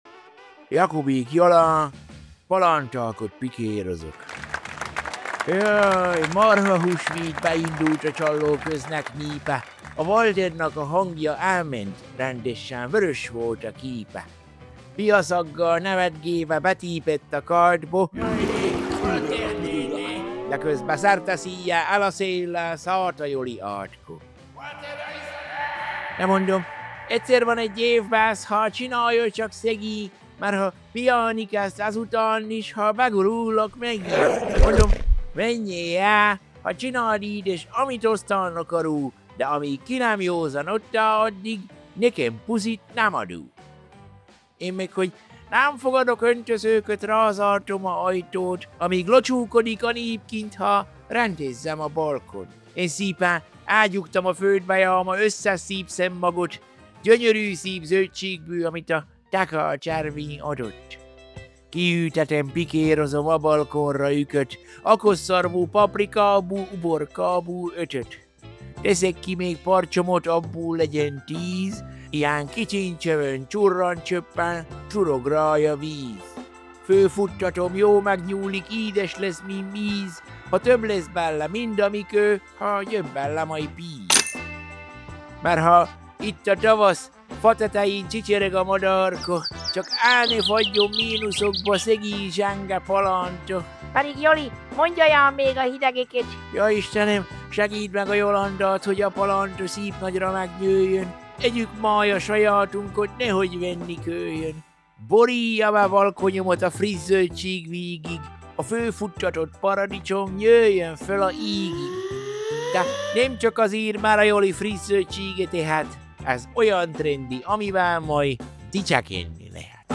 Szaval a jó öreg csallóközi Szaval a jó öreg csallóközi - Palántákot pikérozok Apr 07 2026 | 00:02:28 1x 00:00 / 00:02:28 Subscribe Share RSS Feed Share Link Embed